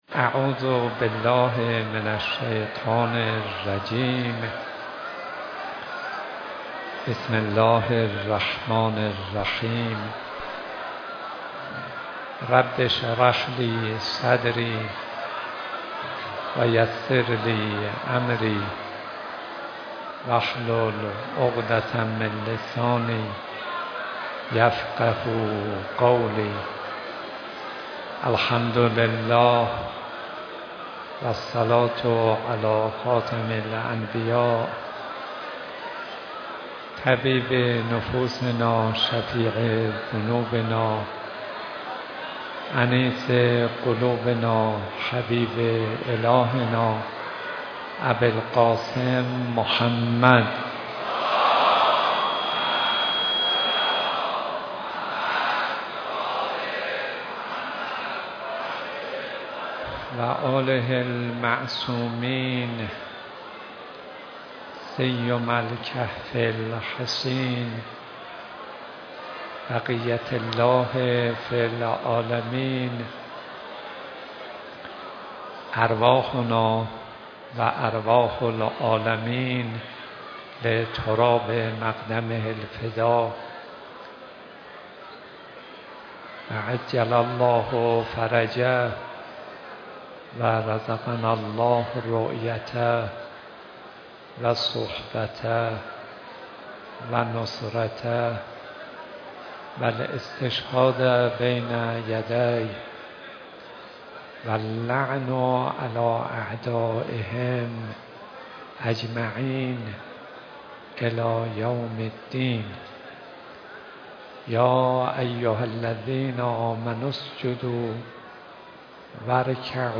سخنرانی استاد صدیقی در مداحی حاج محمد رضا طاهری در بیت رهبری
دومین شب مراسم سوگواری دخت گرامی پیامبر اسلام، حضرت فاطمه زهرا سلام‌الله‌علیها با حضور حضرت آیت‌الله خامنه‌ای رهبر معظم انقلاب اسلامی در حسینیه امام خمینی رحمه‌الله برگزار شد. در این مراسم که اقشار مختلف مردم و جمعی از مسئولان حضور داشتند حجت الاسلام والمسلمین صدیقی سخنرانی و آقای محمدرضا طاهری نیز مرثیه سرایی کردند.